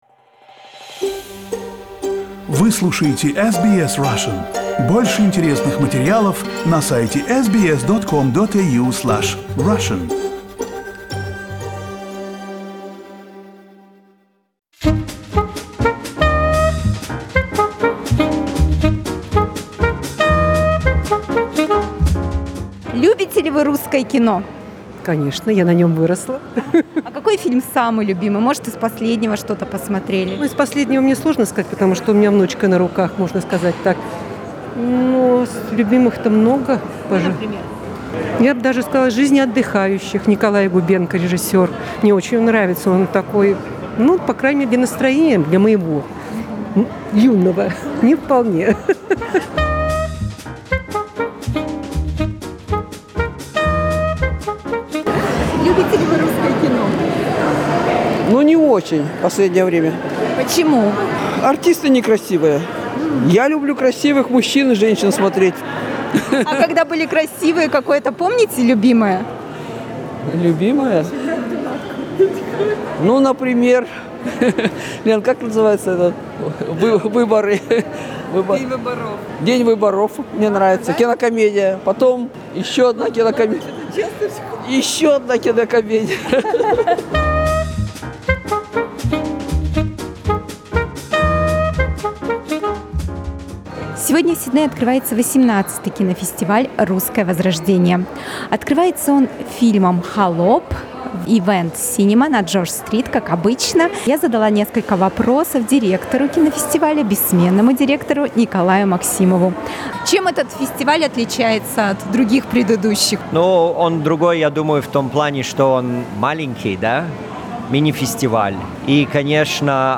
The 18th Russian Resurrection Film Festival kicked off in Sydney on Thursday. Listen to the report from the opening night on SBS Russian.